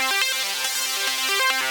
Index of /musicradar/shimmer-and-sparkle-samples/140bpm
SaS_Arp05_140-C.wav